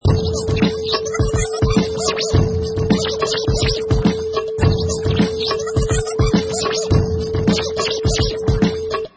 Professional killer vinyl scratch on top of hip hop beat, perfect for sampling, mixing, music production, timed to 105 beats per minute
Product Info: 48k 24bit Stereo
Category: Musical Instruments / Turntables
Try preview above (pink tone added for copyright).